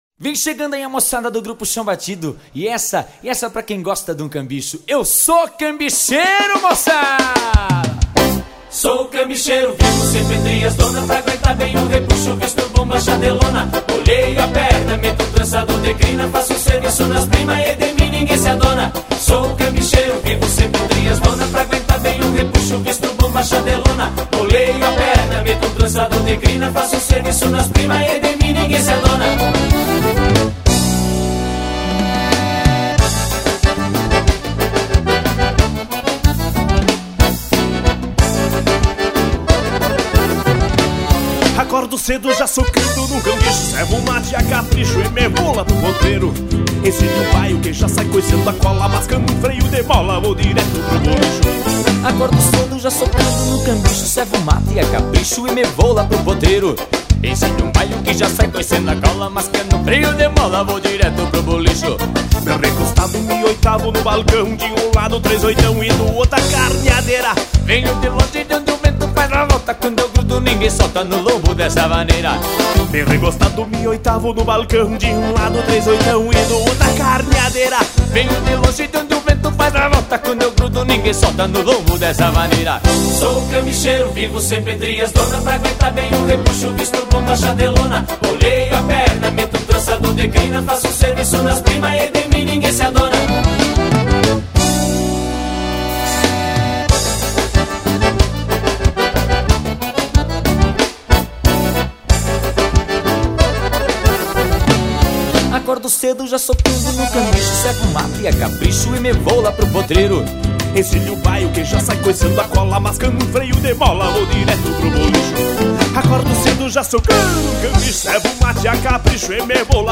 EstiloRegional